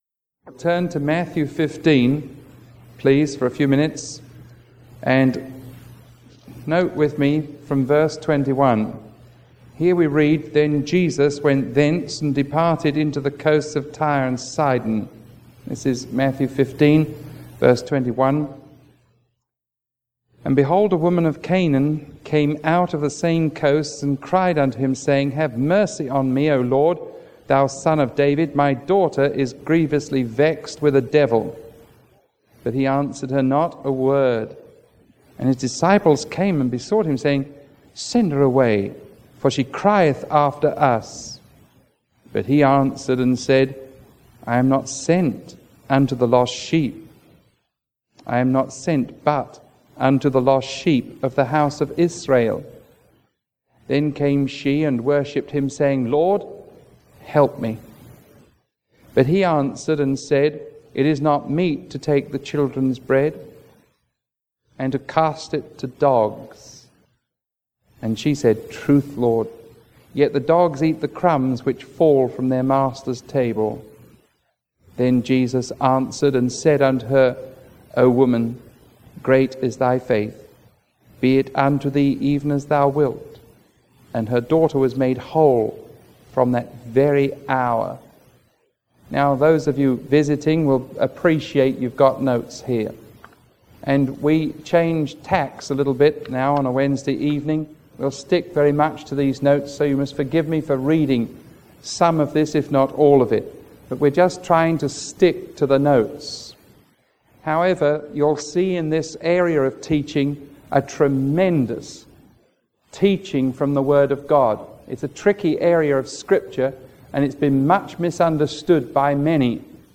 Sermon 0380AB recorded on July 8